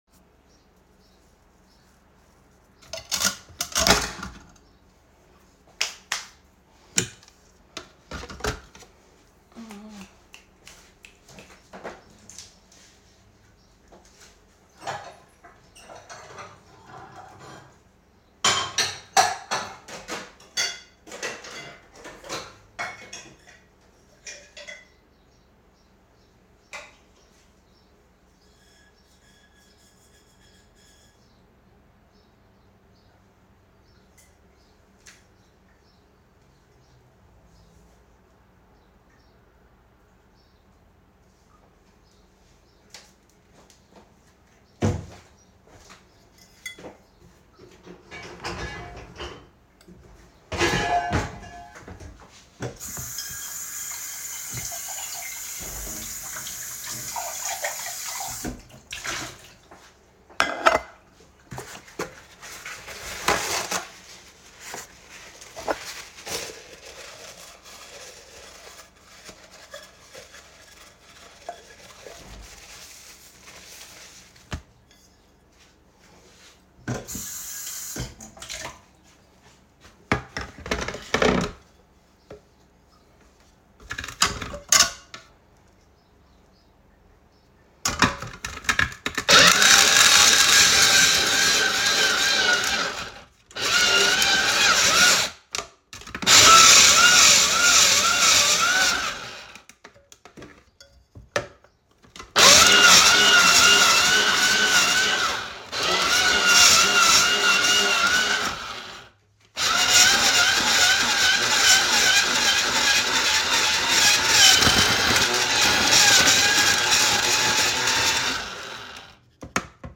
Snowball Machine Asmr Sound Effects Free Download